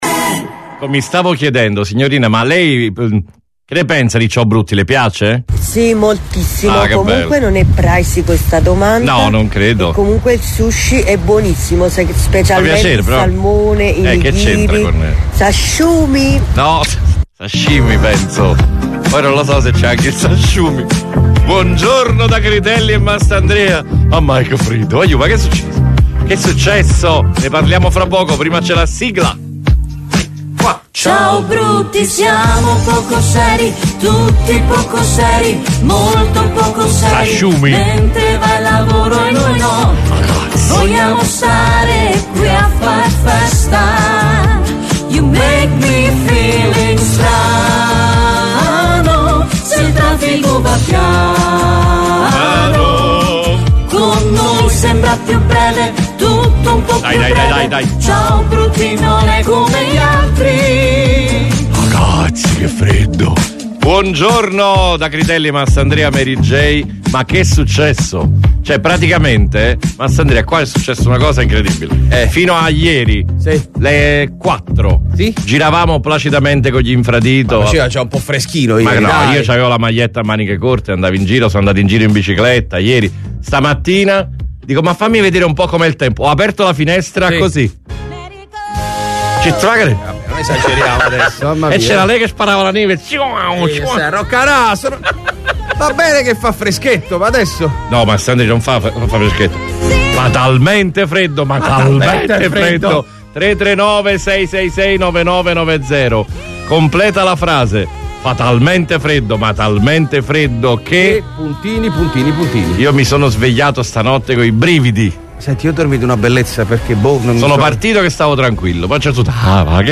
IL MORNING SHOW DI RADIO MARTE